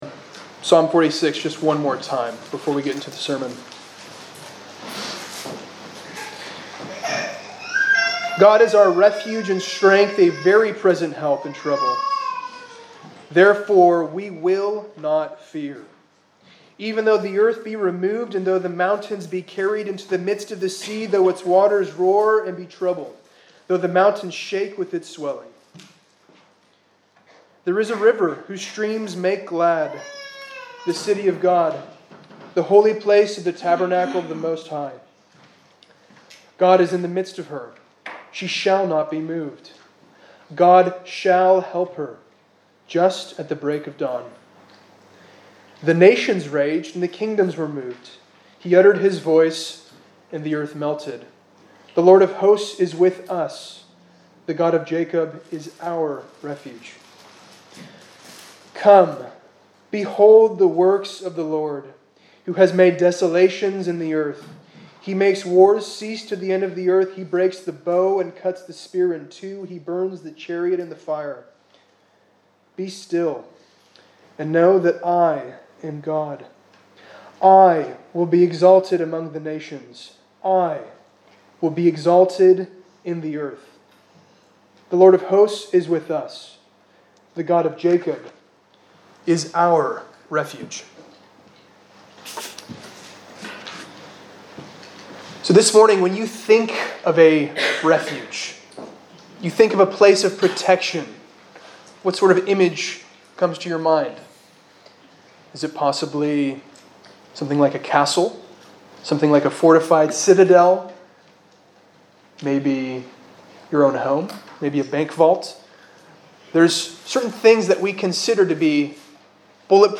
2019 Service Type: Sunday Morning Speaker
Single Sermons Book: Psalms Scripture: Psalm 46 https